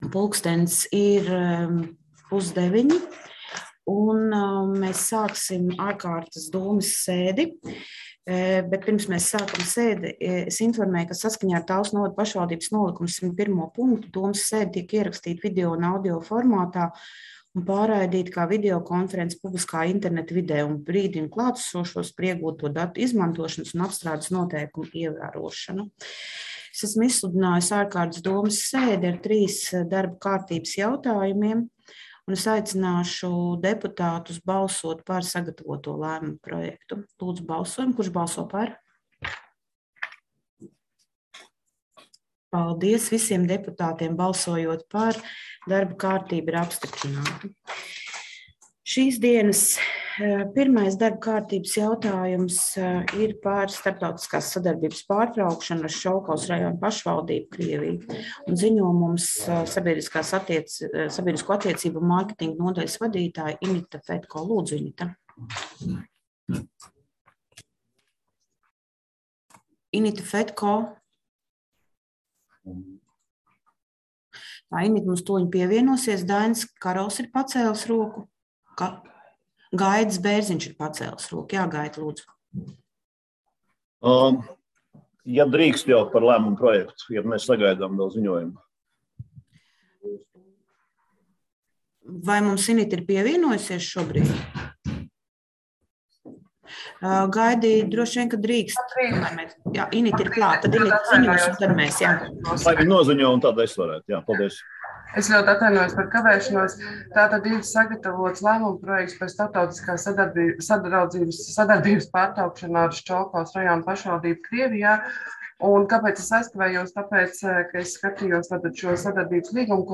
Balss ātrums Publicēts: 25.02.2022. Protokola tēma Domes sēde Protokola gads 2022 Lejupielādēt: 6.